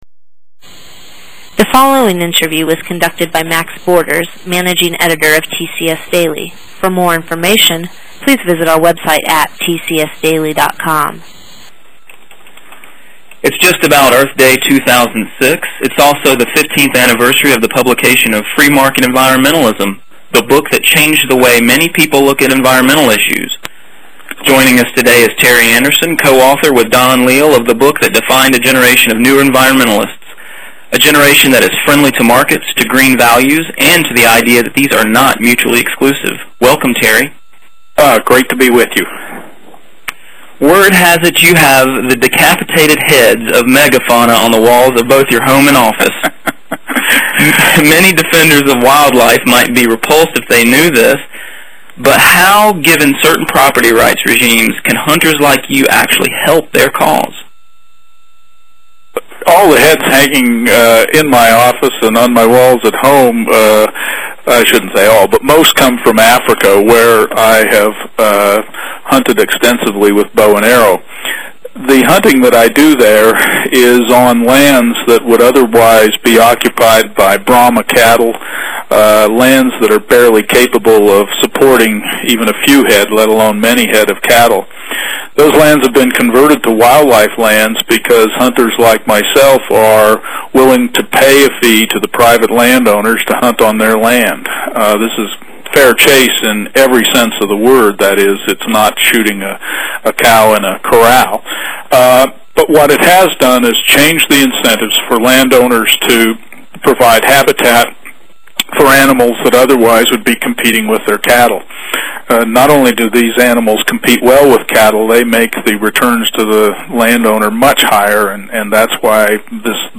TCS Spotlight: Interview